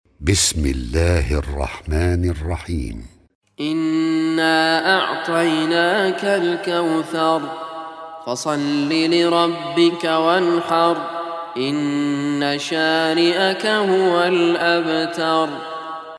Surah Kausar MP3 Recitation by Raad Kurdi
Surah Kausar, is 108 chapter of Holy Quran. Listen beautiful recitation of Surah Kausar free mp3 in best audio quality.